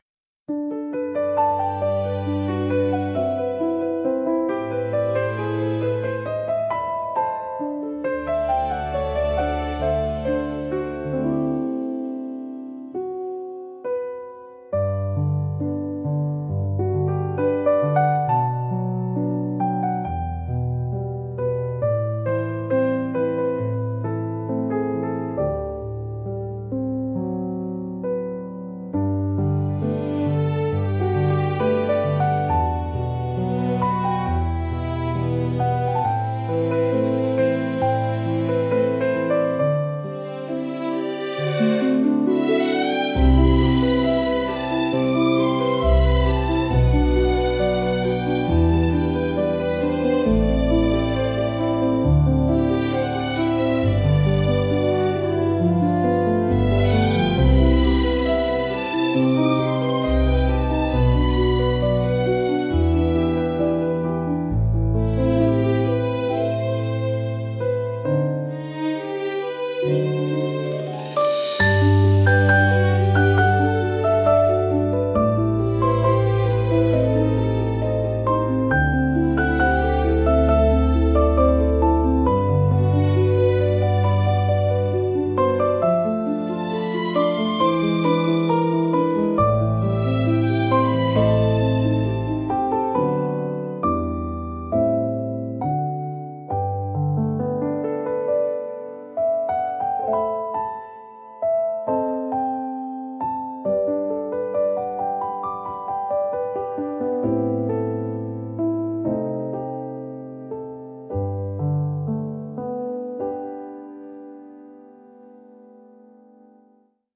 静かで清々しい空気感を演出しつつ、心に明るい希望や期待を芽生えさせる効果があります。